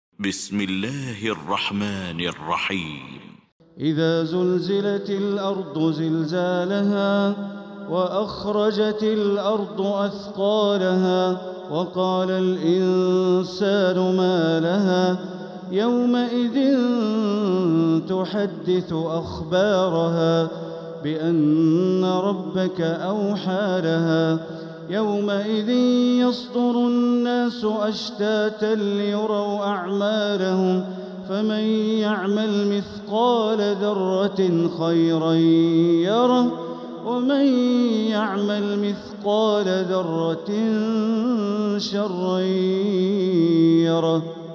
المكان: المسجد الحرام الشيخ: معالي الشيخ أ.د. بندر بليلة معالي الشيخ أ.د. بندر بليلة الزلزلة